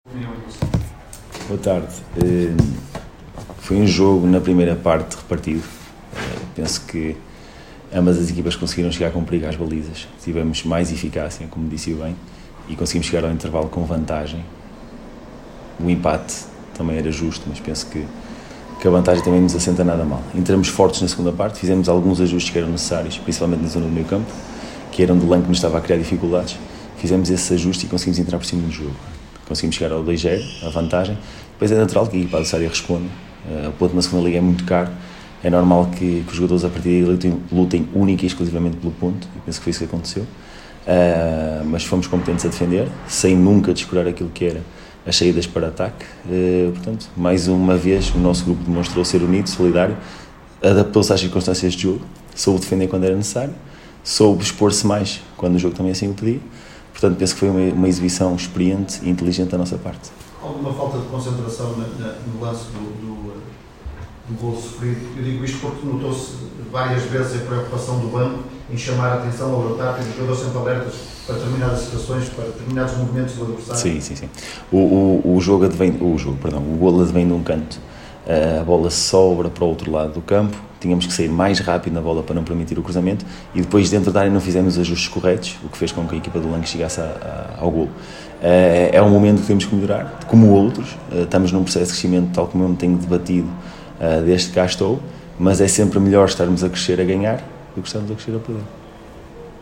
Na conferência de imprensa realizada no final do encontro